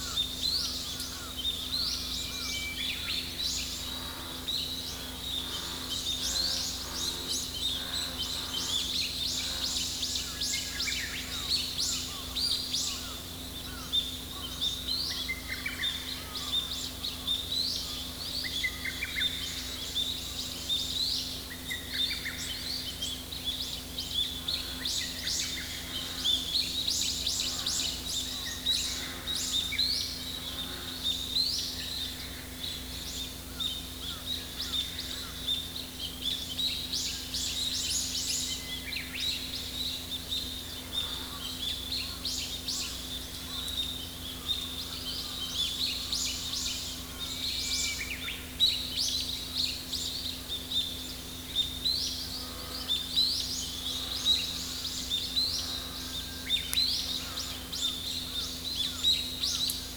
<立田山の鳥たちの啼き声（朝）=WAVEサウンドです。>（WAV：10,334KB）